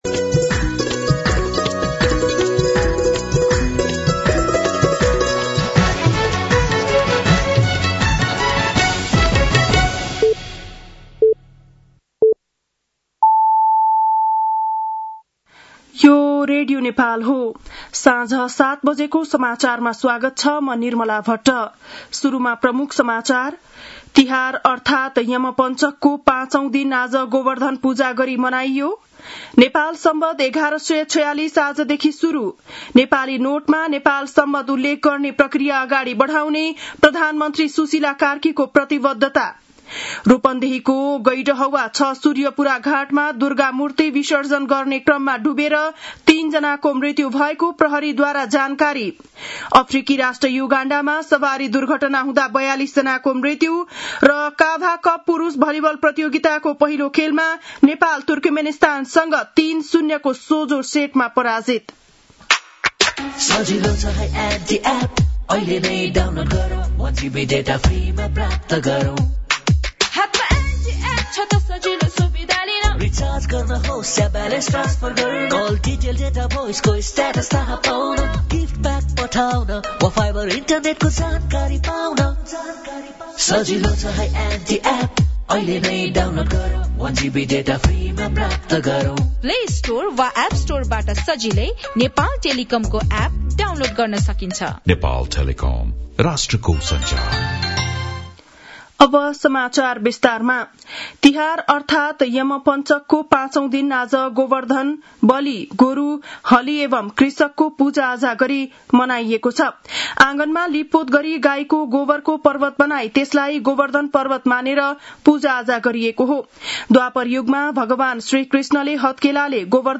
बेलुकी ७ बजेको नेपाली समाचार : ५ कार्तिक , २०८२
7-PM-Nepali-NEWS-07-05.mp3